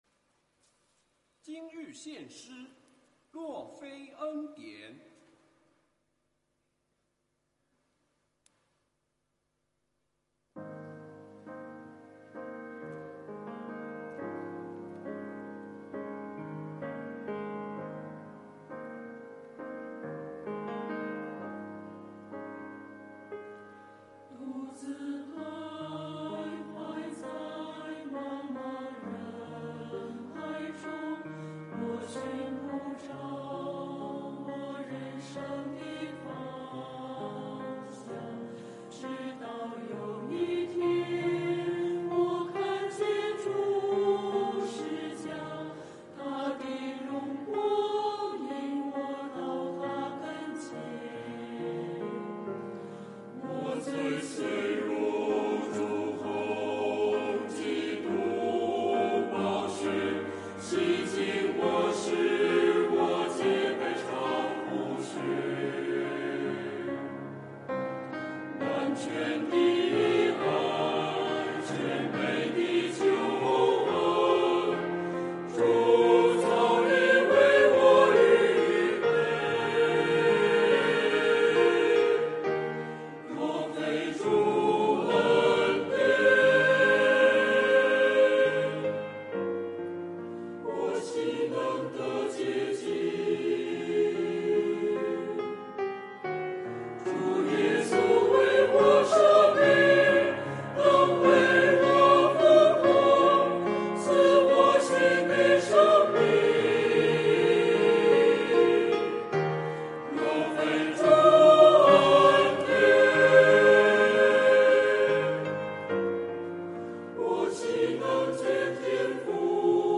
团契名称: 大诗班 新闻分类: 诗班献诗 音频: 下载证道音频 (如果无法下载请右键点击链接选择"另存为") 视频: 下载此视频 (如果无法下载请右键点击链接选择"另存为")